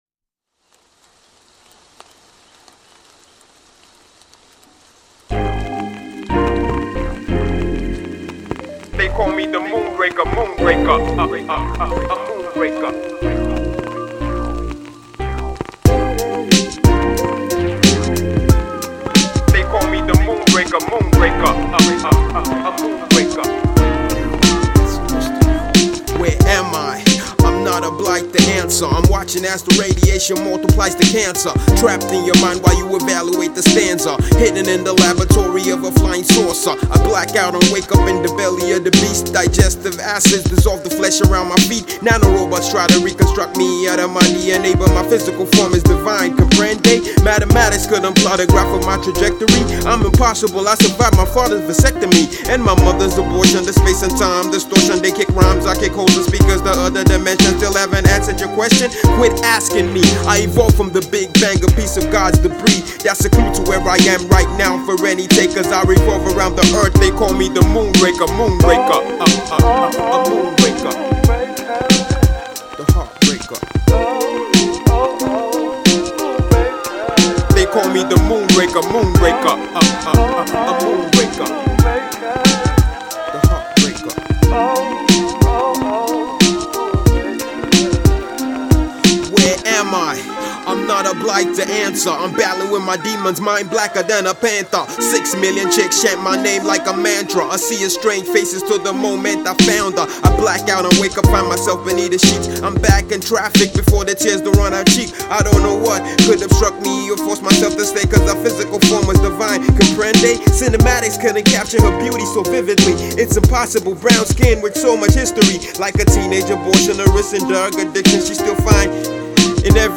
Note*recommended for Hip-Hop Heads…